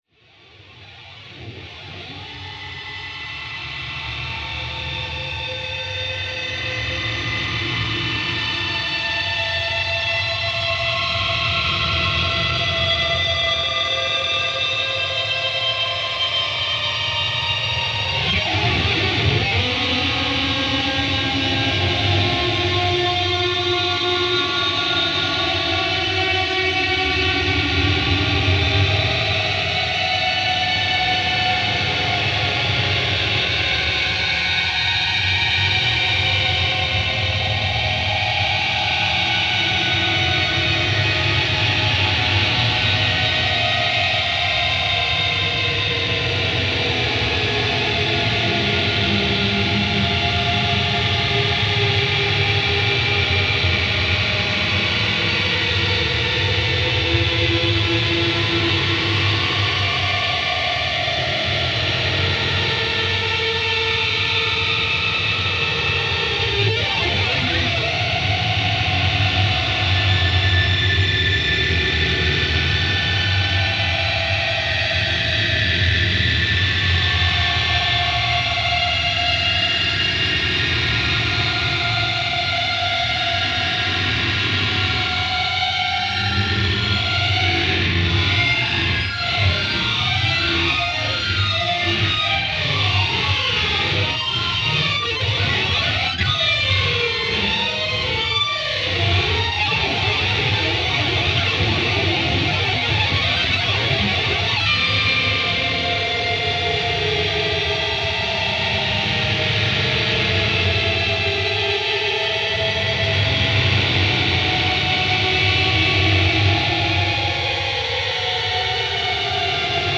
ギター愛好家の方々にはもちろん、現代音楽、先端的テクノ、実験音楽をお好きな方々にもお薦めのアルバムです。